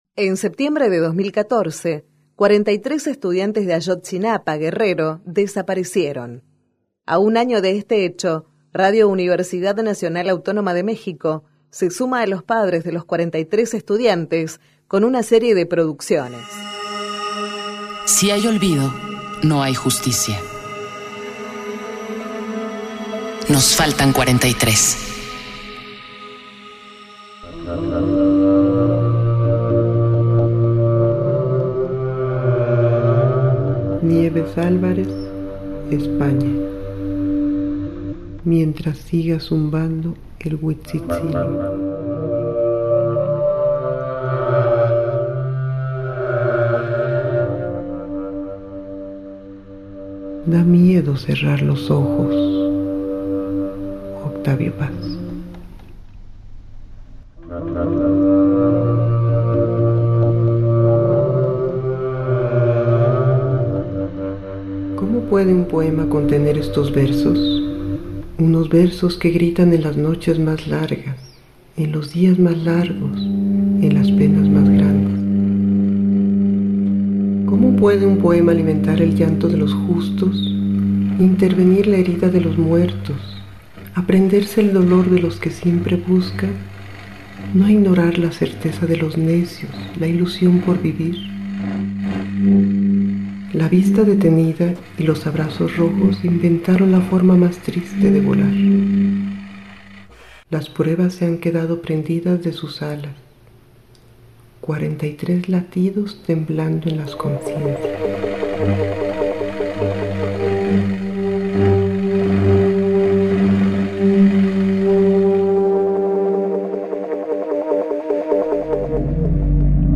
A un año de los sucesos, la producción de Radio UNAM reúne distintas personalidades del ámbito radiofónico que, entre poesía, música y ambientes sonoros, rescatan la memoria viva del sonado caso de los estudiantes desaparecidos de la Escuela Normal Rural de Ayotzinapa.